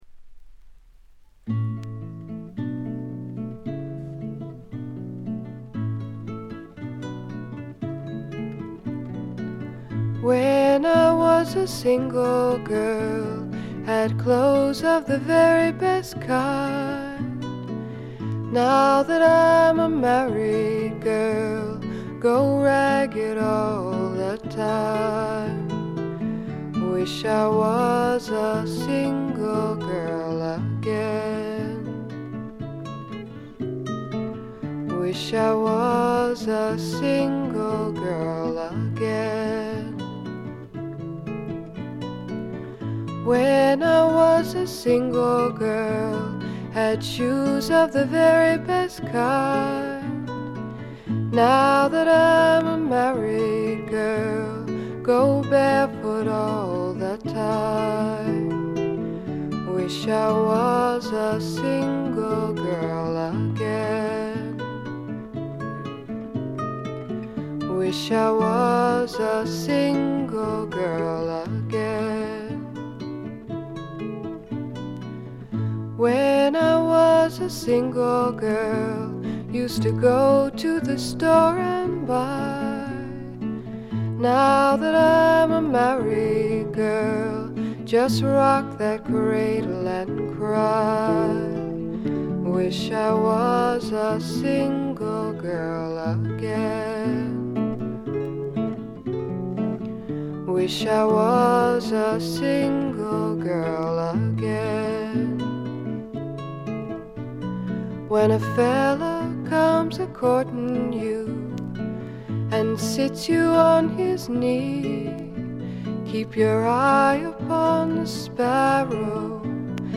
ちょっとハスキーな美声ではかなげに歌われる宝石のような歌の数々。
試聴曲は現品からの取り込み音源です。
guitar
flute
dobro guitar